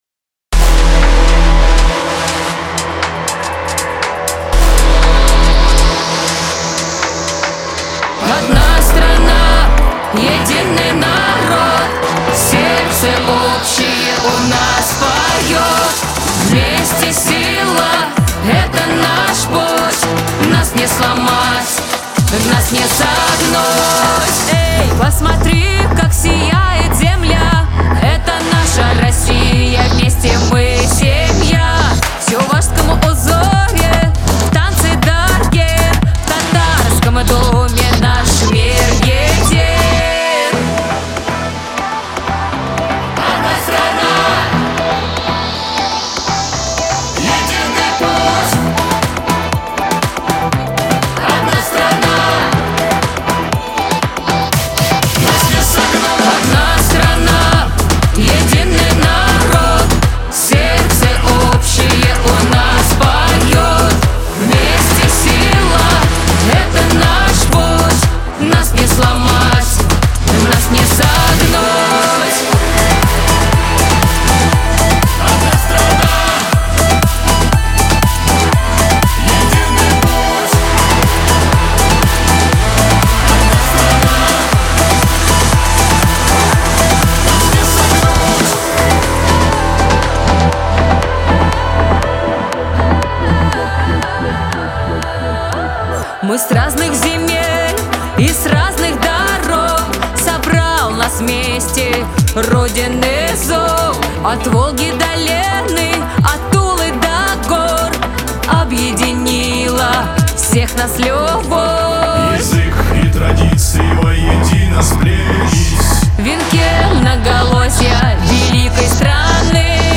ансамбль , эстрада